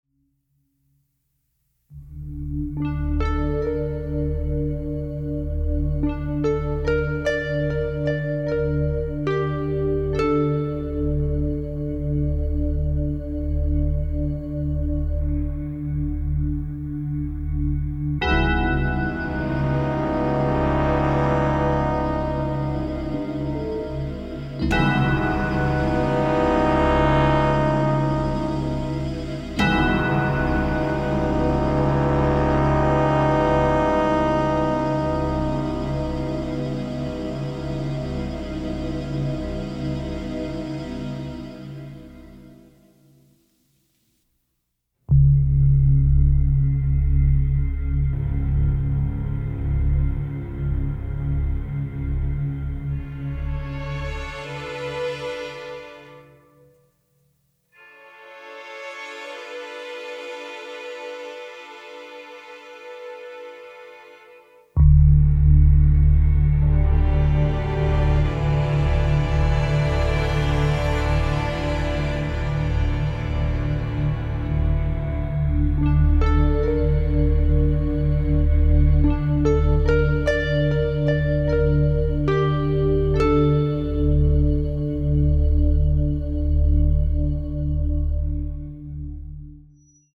stereo presentation
original motion picture score